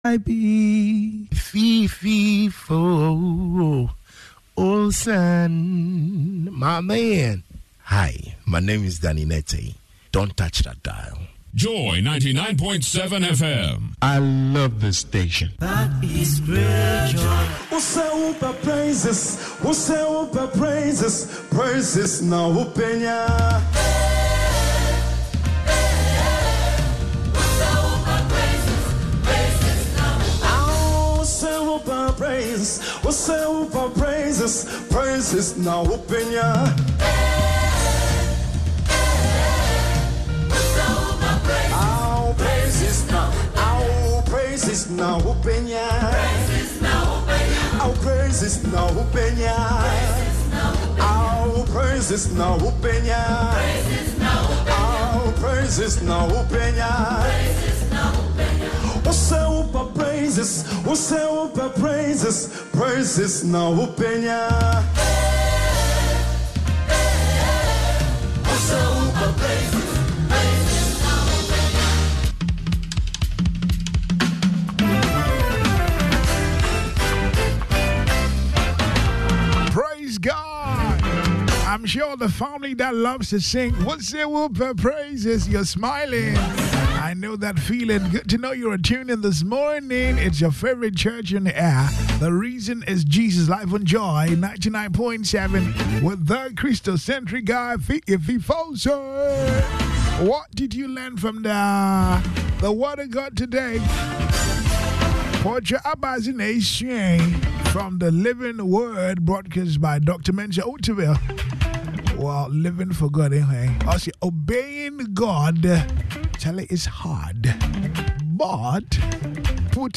Christian music show interspersed with motivational quotes and Bible verses